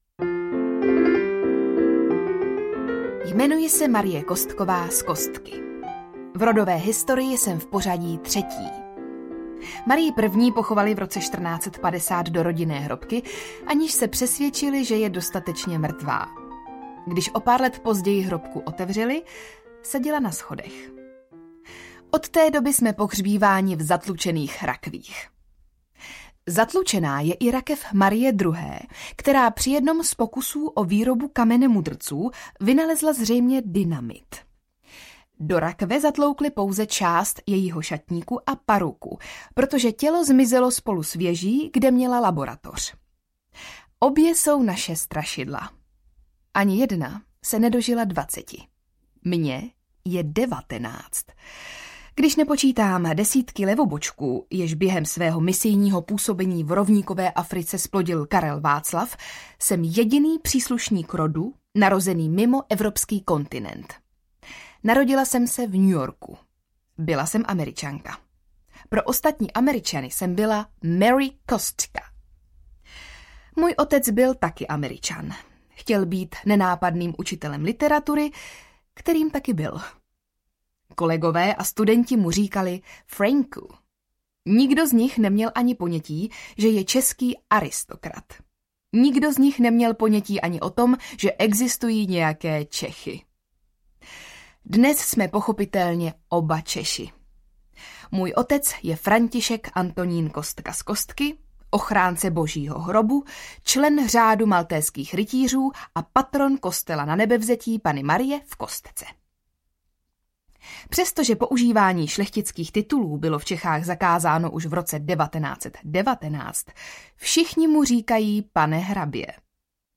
Aristokratka a vlna zločinnosti na zámku Kostka audiokniha
Ukázka z knihy